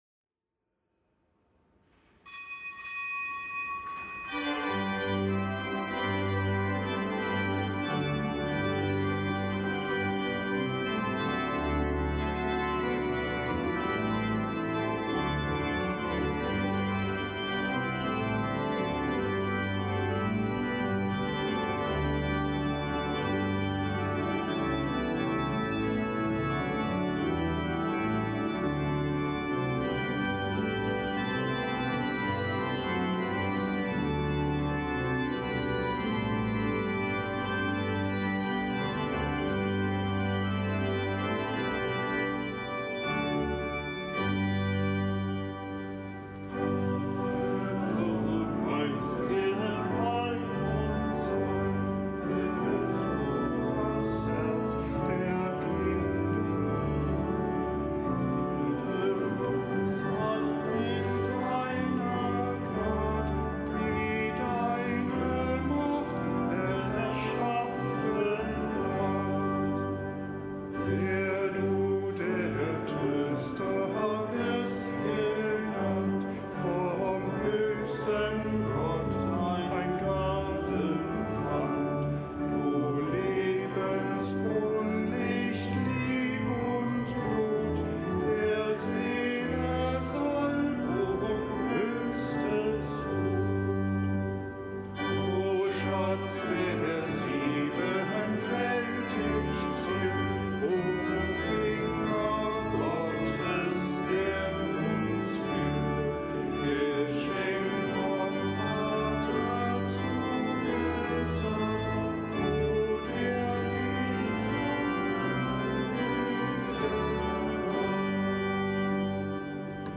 Kapitelsmesse am Dienstag der zehnten Woche im Jahreskreis
Kapitelsmesse aus dem Kölner Dom am Dienstag der zehnten Woche im Jahreskreis